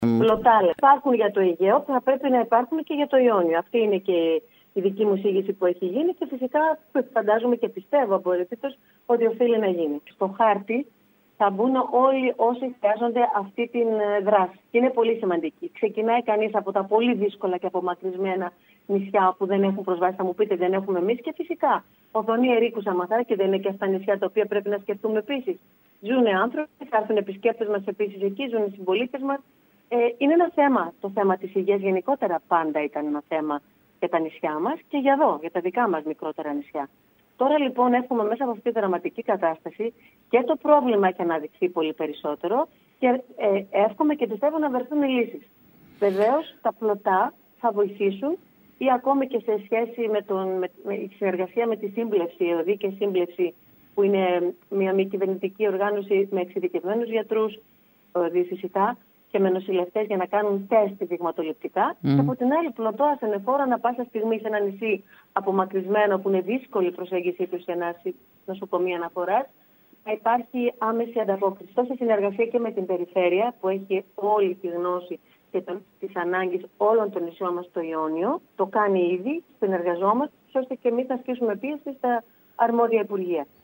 Την πρόθεση της να συνεργαστεί με τους τοπικούς φορείς ώστε να ασκήσουν πιέσεις στα αρμόδια Υπουργεία για να υπάρξει πλωτό ασθενοφόρο και στο Ιόνιο δήλωσε η Άντζελα Γκερέκου Πρόεδρος του ΕΟΤ μιλώντας σήμερα στην ΕΡΑ ΚΕΡΚΥΡΑΣ. Η πρόεδρος του ΕΟΤ είπε ότι θεωρεί σημαντική την αίσθηση της ασφάλειας του επισκέπτη και για το λόγο αυτό η κυβέρνηση καθιερώνει τα πλωτά ασθενοφόρα για τα μικρά νησιά του Αιγαίου αλλά αυτό πρέπει να γίνει και για τα Διαπόντια και τους Παξούς.